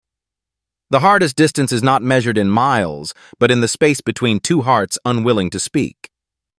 🎤 Emotional Quote TTS 🎵 Background Music
tts_9c877afa3b.mp3